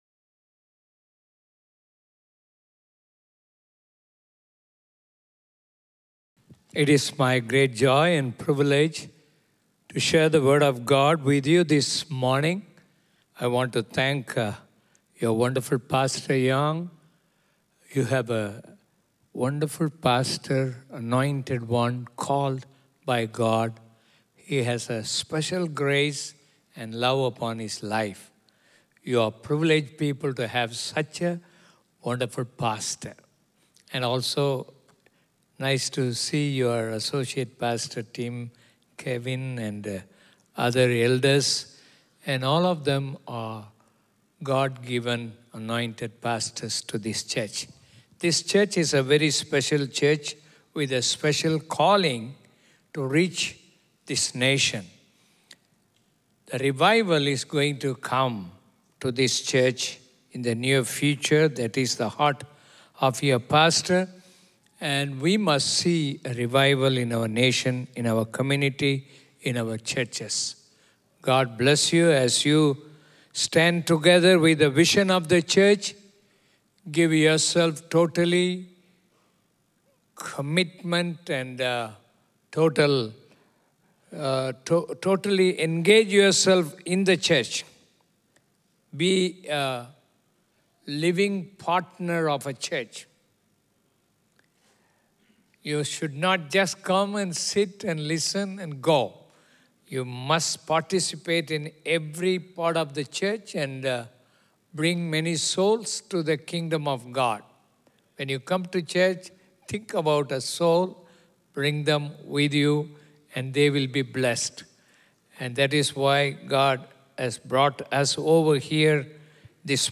Weekly audio sermons from Cornerstone Community Church in Singapore